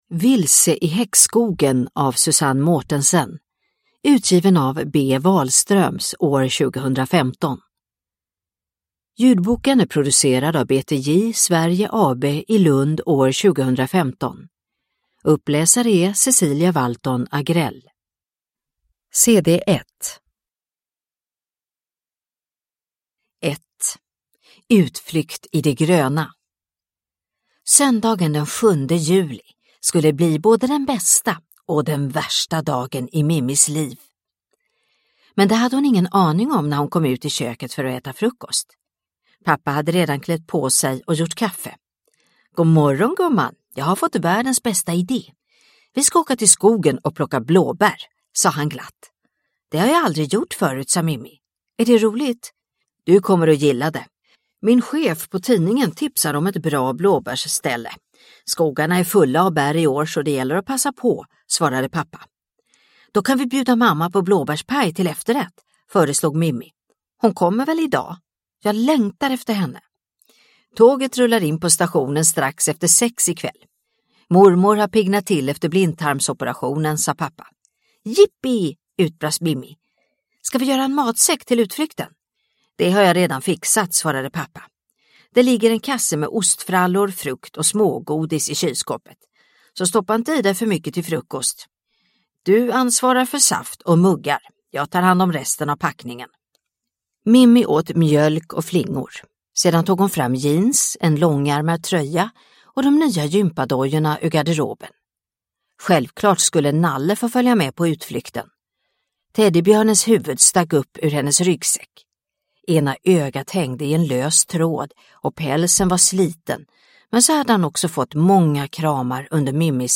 Vilse i häxskogen – Ljudbok – Laddas ner